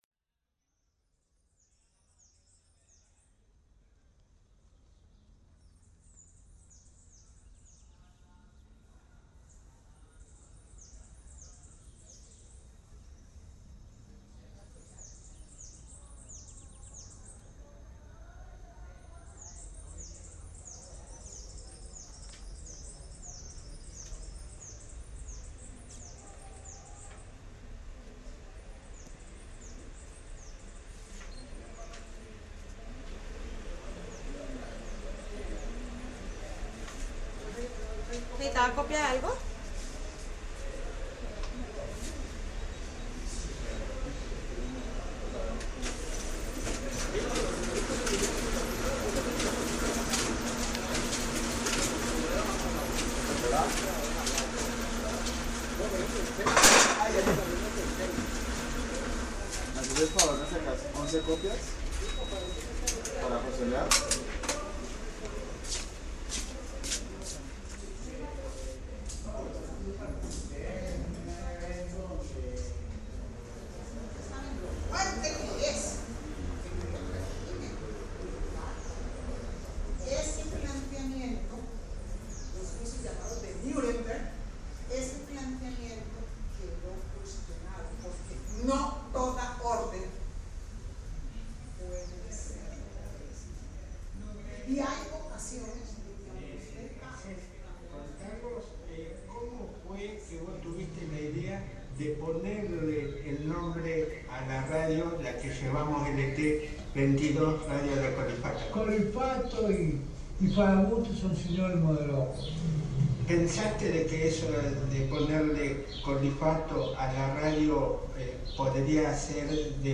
Paisaje_Universidades.MP3 (7.96 MB)
Paisaje sonoro grabado en el campus de la Universidad del Valle donde conviven estudiantes de diversas disciplinas que van desde las ingenierías hasta la música. Registra la vida universitaria, espacios como la biblioteca, los pasillos y los exteriores.